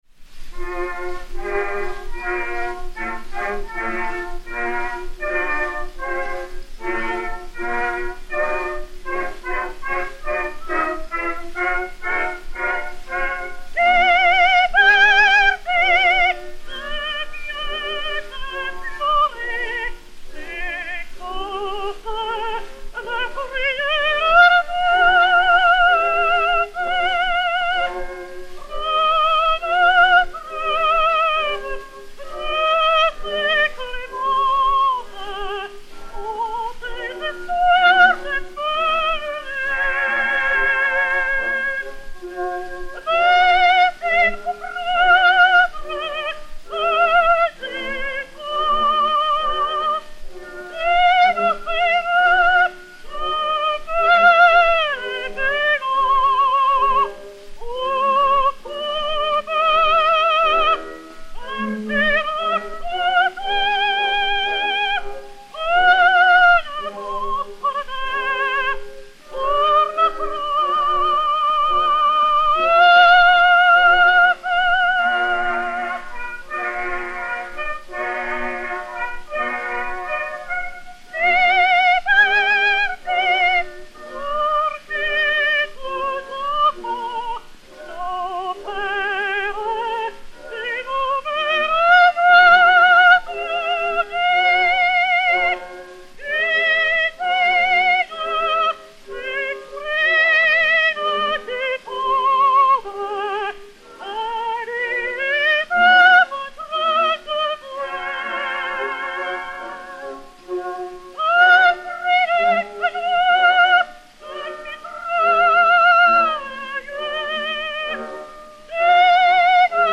Marie Delna (Marion, créatrice) et Orchestre
Pathé saphir 90 tours n° 4873, réédité sur 80 tours n° 55, enr. en 1907